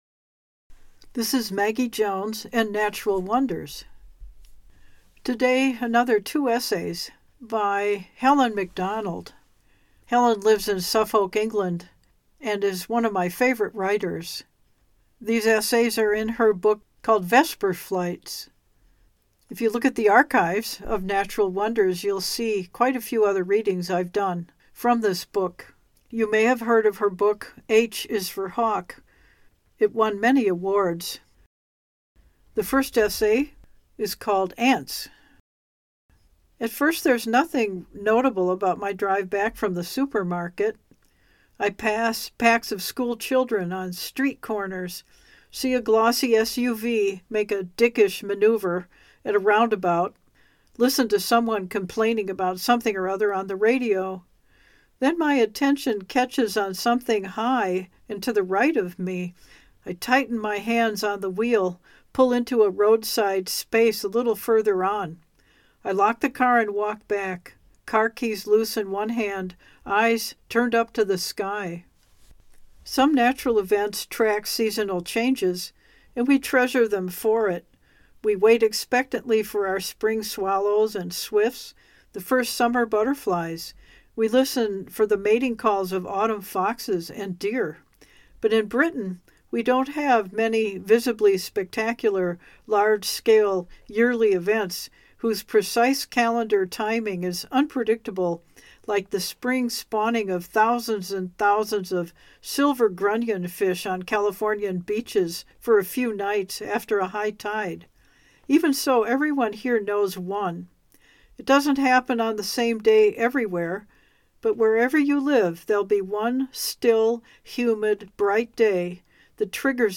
Vesper Flights by Helen Macdonald again today is featured. I’ll read 2 essays, Ants, and What Animals Taught Me.